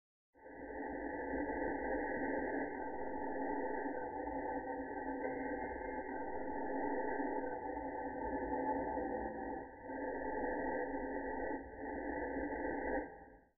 Объясните природу шума при записи скрипки
Своеобразный, чем-то напоминающий дыхание.